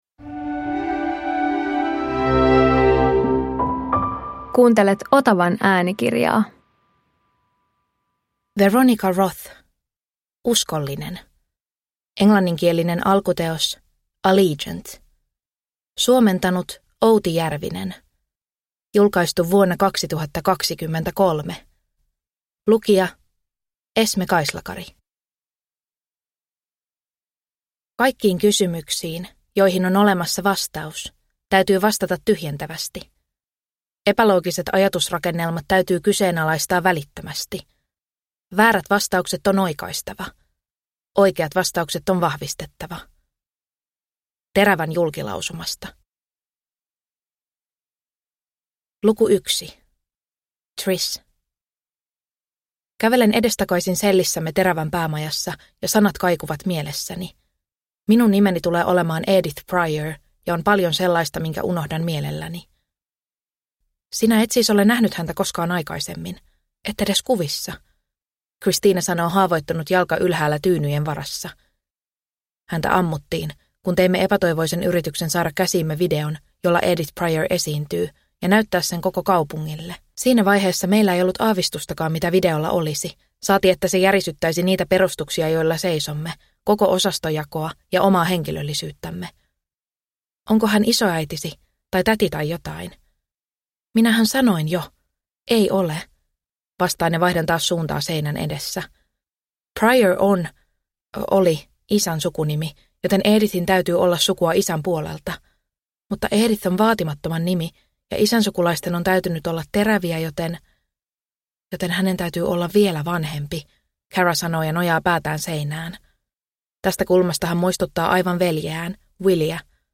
Uskollinen – Ljudbok – Laddas ner